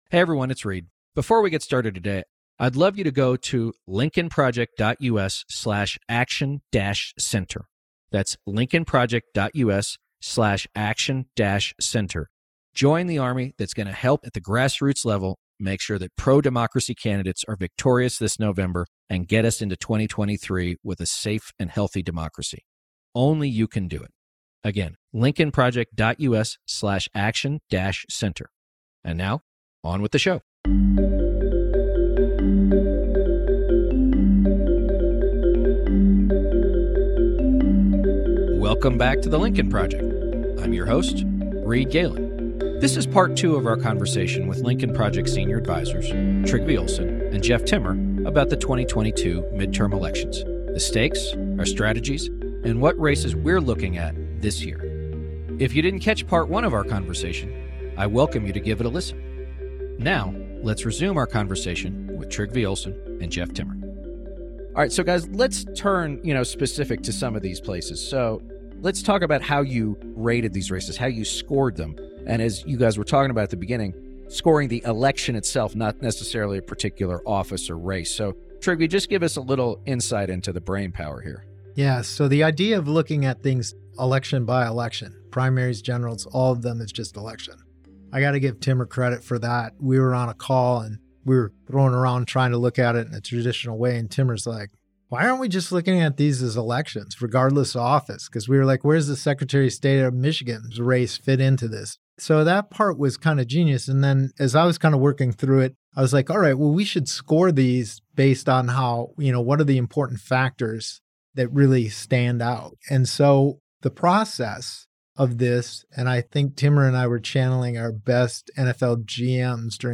in-depth conversation about the 2022 midterm elections. To start, the panel gives their criteria for how they score the upcoming 2022 elections and the unique decision to score them not on a scale of “Democrat to Republican” but rather on a scale of “Democracy to Autocracy”.